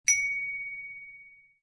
break_alert.wav